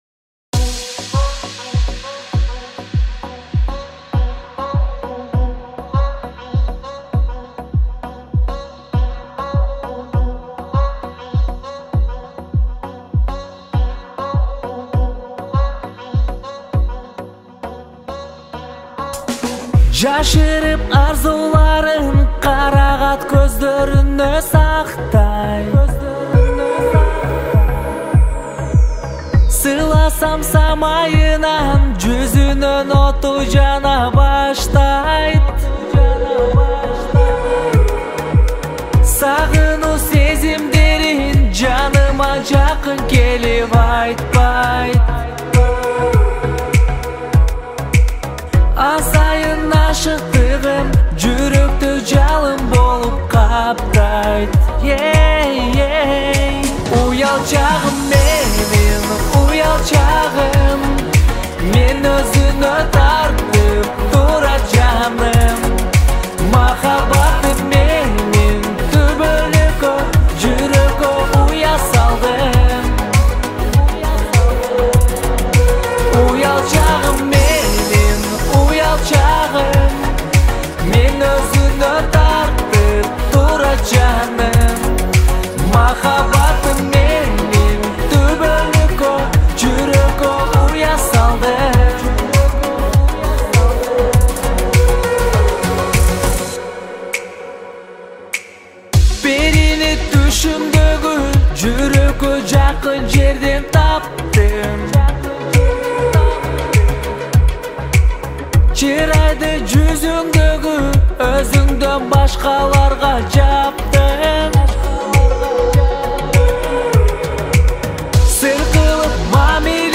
• Категория: Киргизские песни